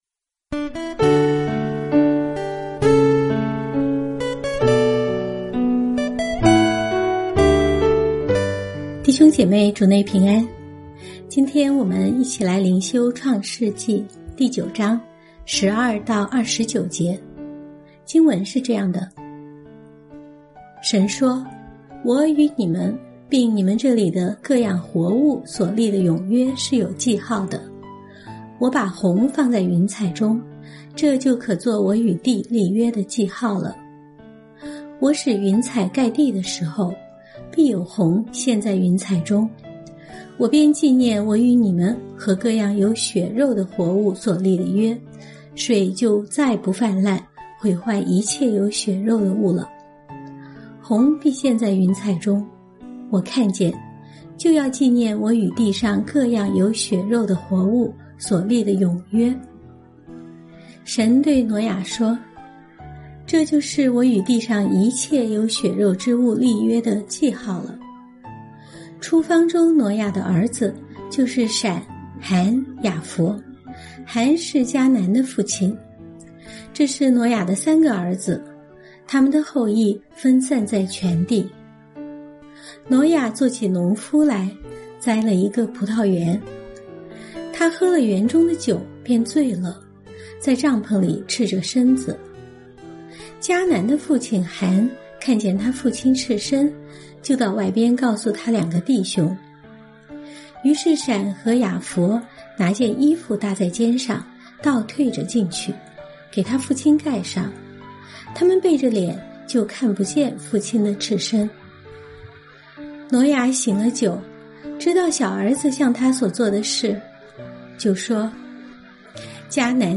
每天閱讀一段經文，聆聽牧者的靈修分享，您自己也思考和默想，神藉著今天的經文對我說什麼，並且用禱告來回應當天的經文和信息。